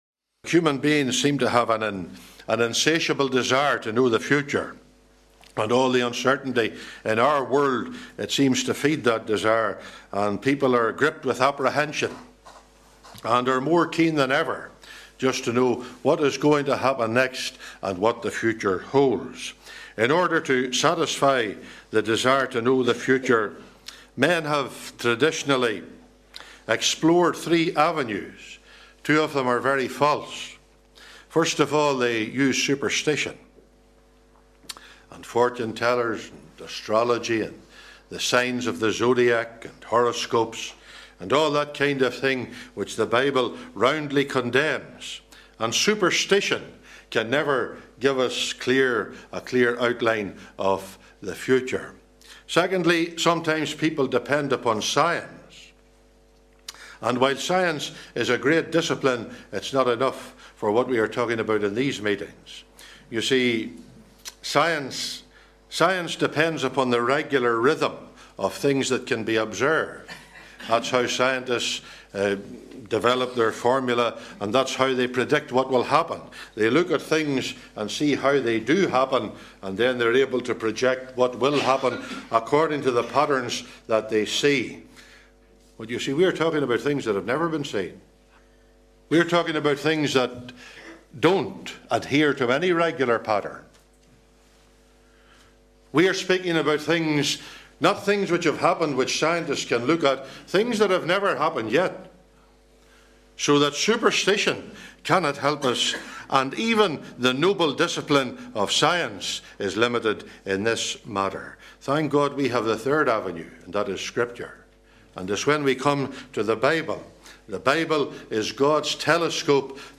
His number is 666 and his short rule will end with him being cast alive into the lake of fire. Readings: 2 Thess 2:1-12, 1 John 2:18-22, Rev 13:1-9, 16.18. (Recorded in Northern Ireland)